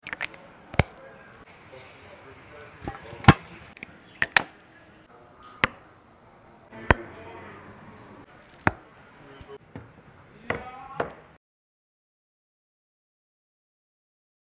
دەنگی📢 کەلەی بۆنەکان لە کاتی sound effects free download